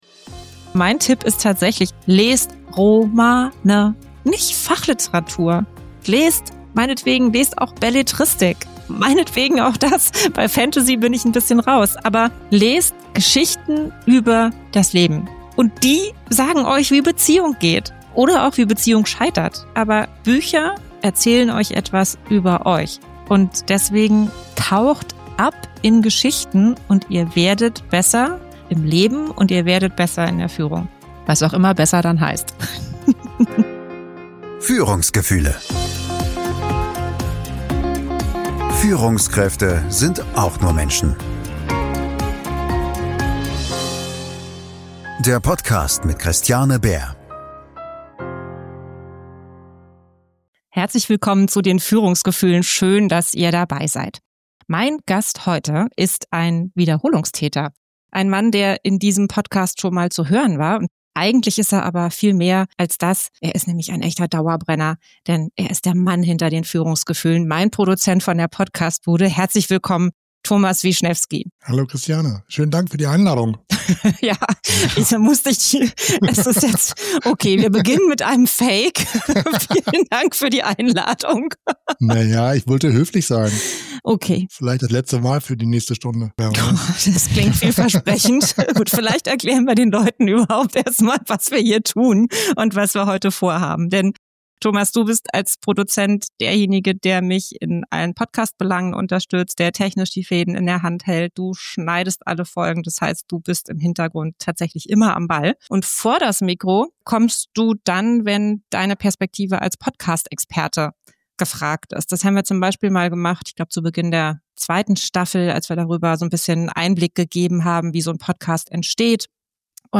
Wir reden über Neugier, Sendungsbewusstsein, Lieblingsgäste, technische Pannen und darüber, was es wirklich braucht, um einen Podcast zu machen. Und natürlich über KI – wo sie uns hilft, wo sie uns fordert und warum am Ende trotzdem kein Algorithmus echtes Zuhören ersetzen kann. Diese Folge ist ein ehrlicher, witziger und ziemlich persönlicher Blick hinter die Kulissen – von zwei Menschen, die seit einem Jahr gemeinsam Führungsgefühle in die Welt schicken.